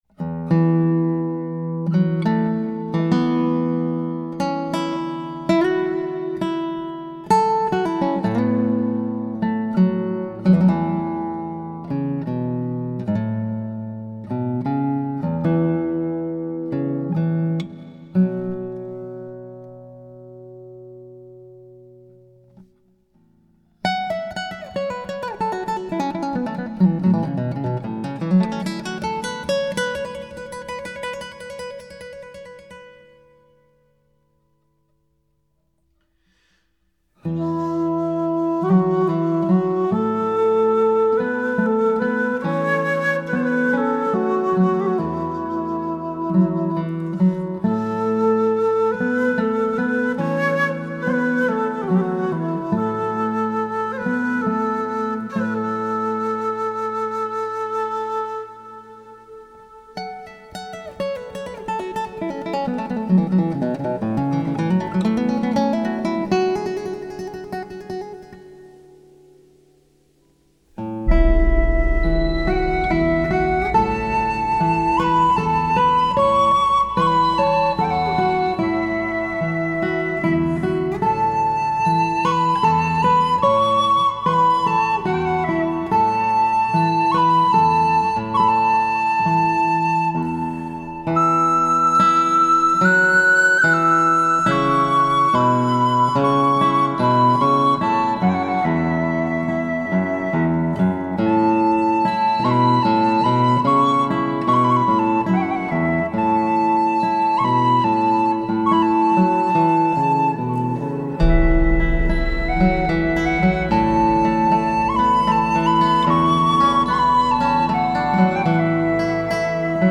Genre: New Age, Relax, Meditative
示範級吹奏技巧一流配樂伴奏
音樂色清脆盡現名曲神髓